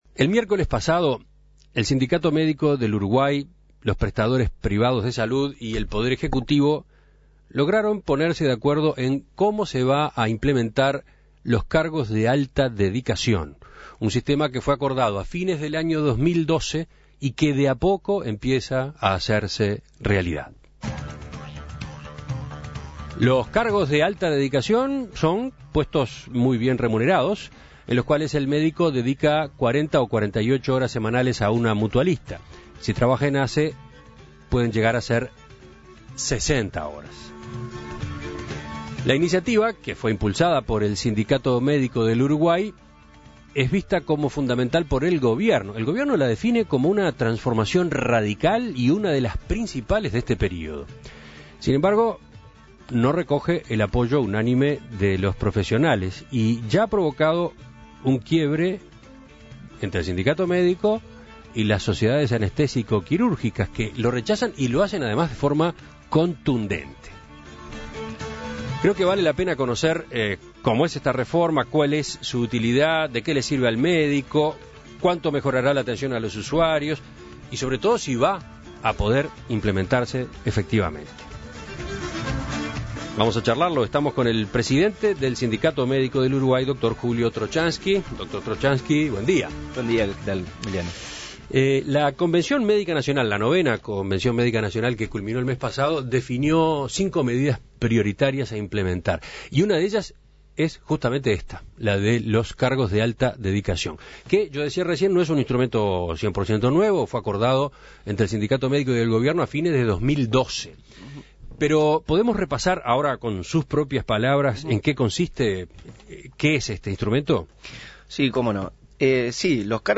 En Perspectiva se propuso averiguarlo, por este motivo entrevistó